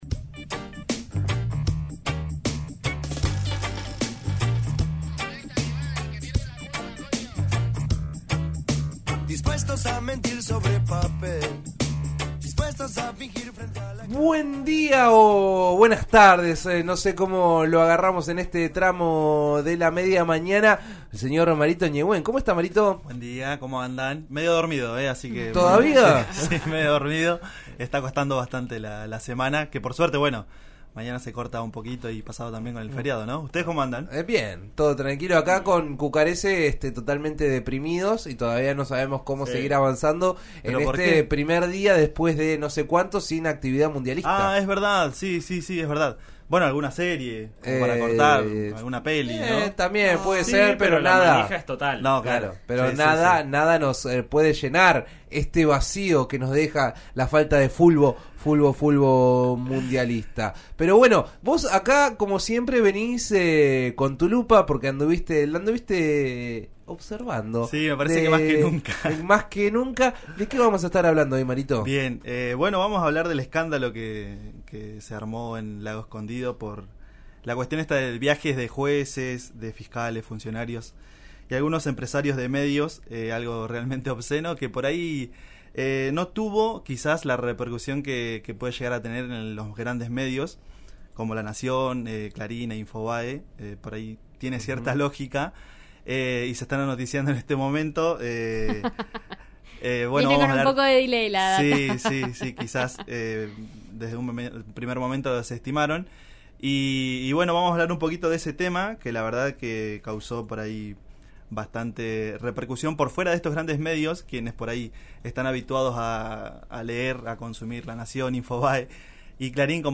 En La Lupa, la columna de En Eso Estamos de RN Radio, conversamos del viaje que habrían realizado un grupo de jueces, fiscales, exfuncionarios públicos y empresarios de medios a la propiedad del magnate inglés Joe Lewis en Lago Escondido.